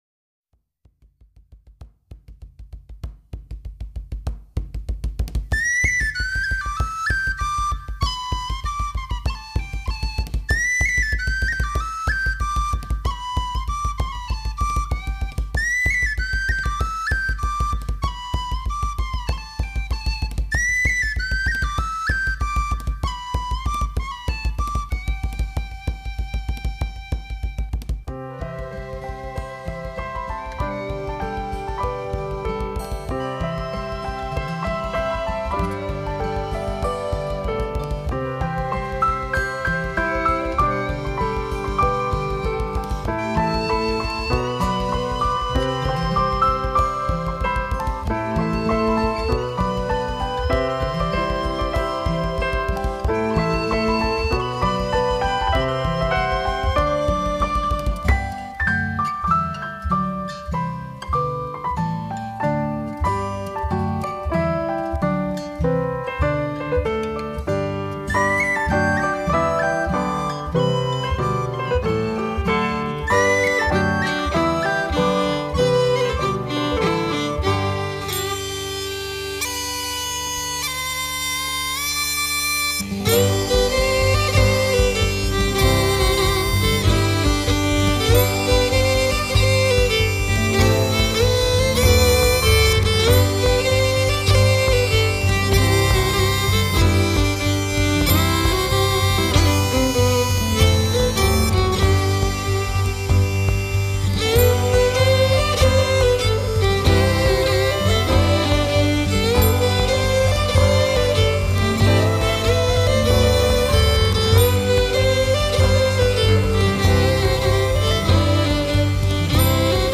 Genre: World
音色甜美，有轻快的圆舞节奏，有醉人的Piano Solo.相当不错。